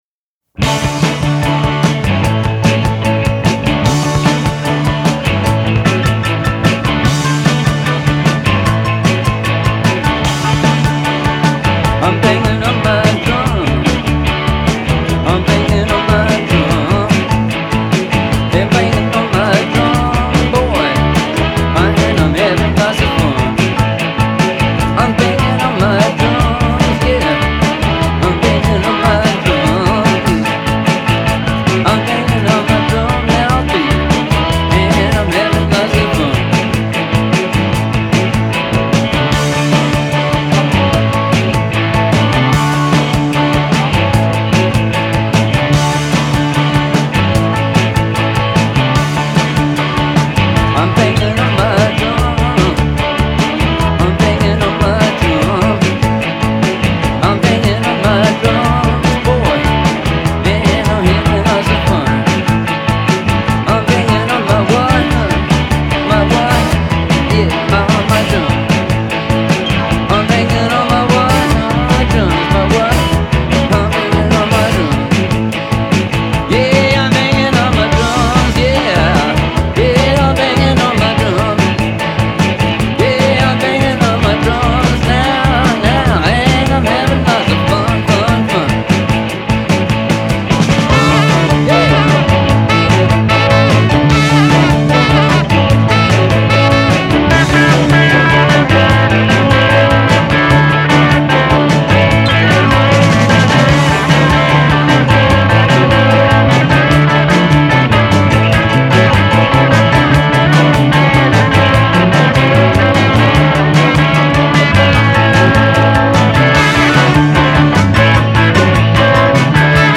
much more guitar heavy than you might expect